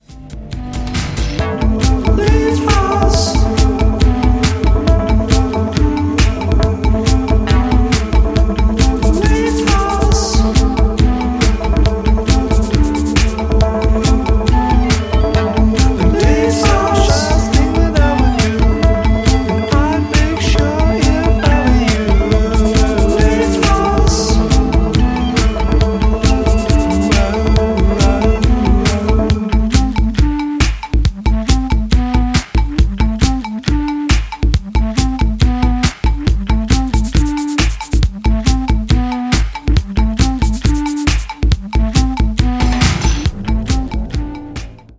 Electro Electronix House